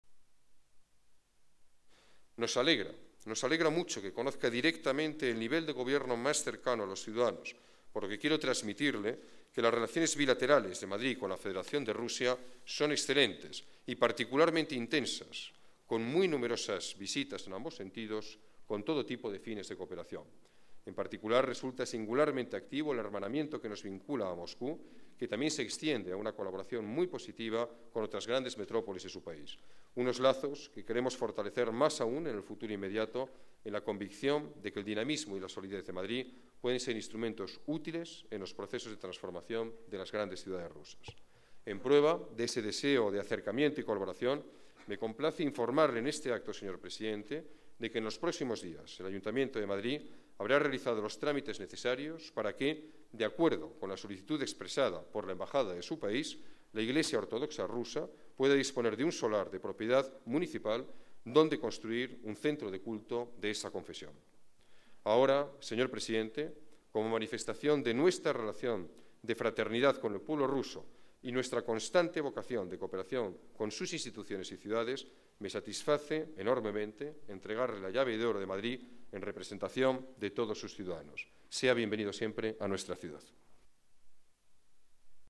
Nueva ventana:Declaraciones alcalde, Alberto Ruiz-Gallardón: visita presidente Rusia, fortalecimiento relaciones